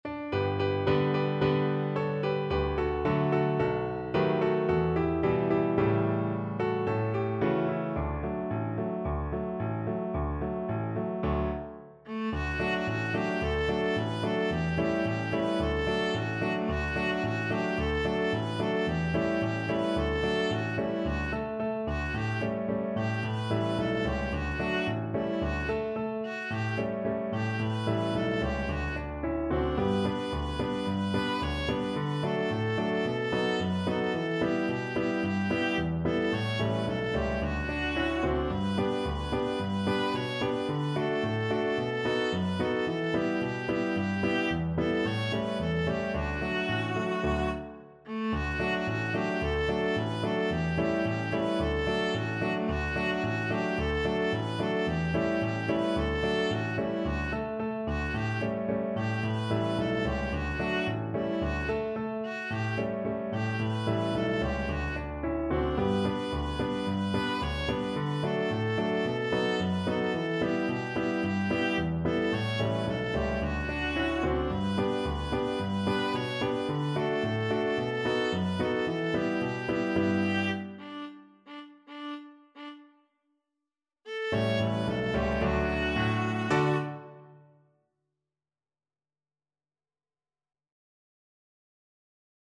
2/4 (View more 2/4 Music)
D major (Sounding Pitch) (View more D major Music for Viola )
Allegro =c.110 (View more music marked Allegro)
Viola  (View more Easy Viola Music)
Croatian